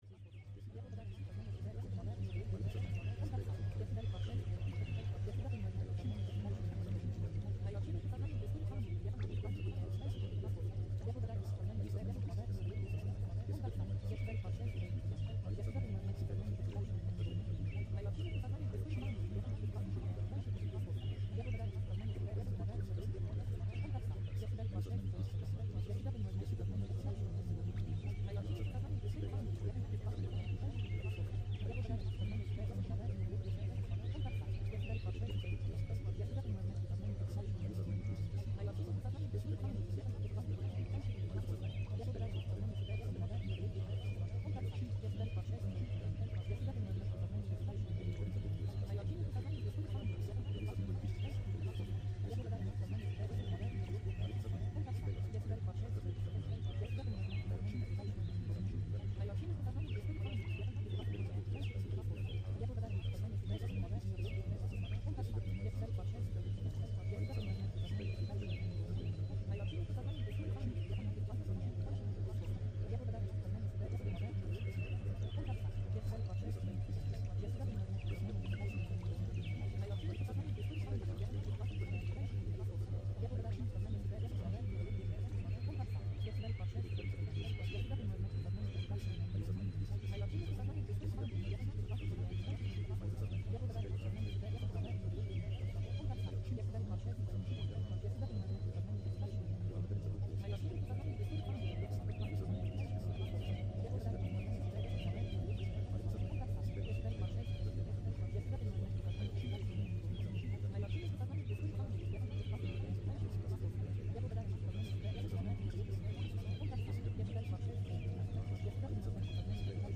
Звуки тета ритма
На этой странице вы найдете аудиозаписи тета-ритмов (4-8 Гц), созданные с помощью изохронных тональностей. Эти звуки помогают синхронизировать мозговую активность, способствуя глубокой релаксации, медитативным практикам и раскрытию творческого потенциала.
Прямой доступ к подсознанию с помощью изохронных тональностей